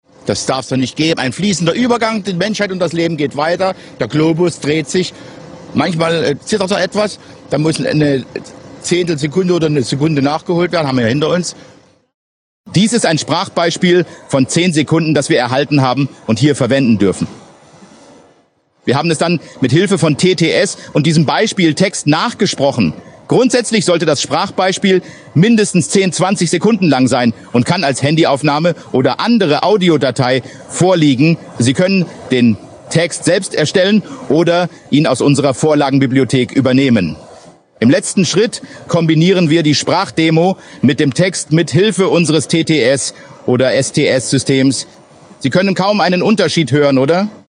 Basis Modell TTS
Das ist die „TTS-Version“ oder auch „Text-to-Speech“ Methode. Damit erreichen sie schon ein hohes Maß an Wiedererkennung der Original Stimme.